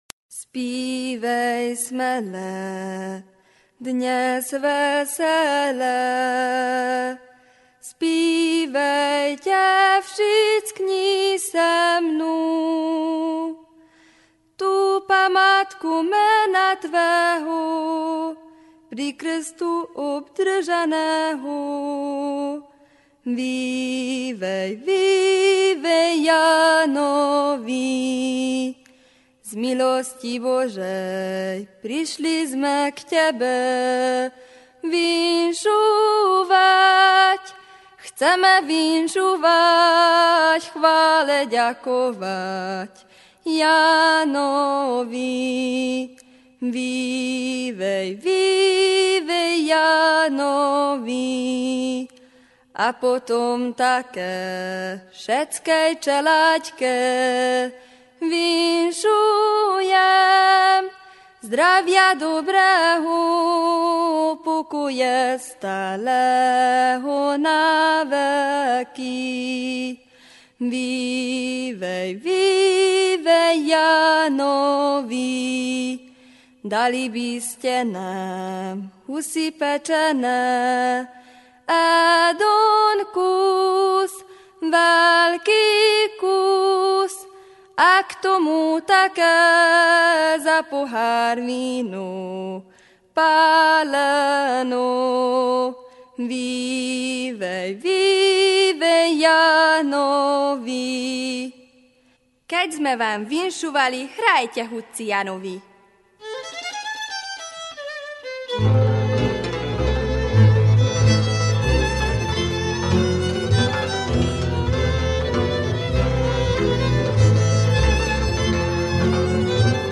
Válogatás mezőberényi népdalokból